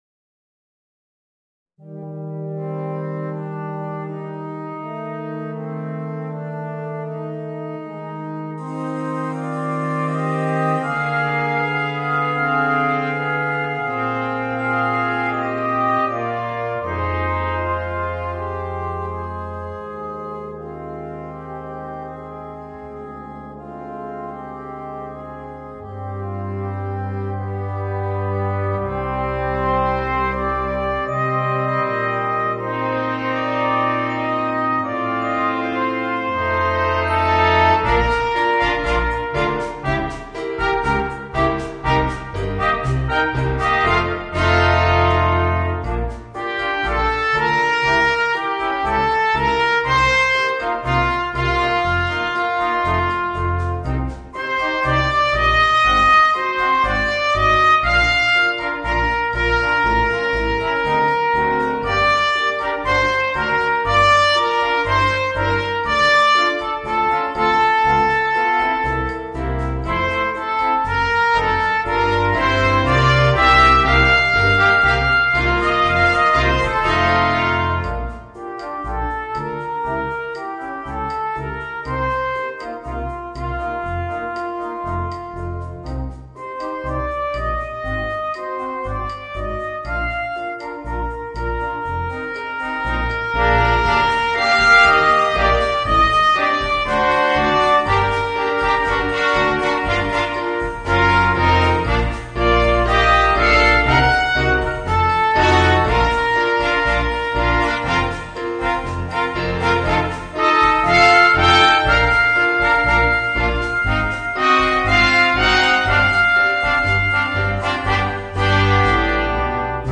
Voicing: 4 - Part Ensemble and Piano